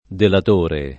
delatore [ delat 1 re ] s. m.; f. ‑trice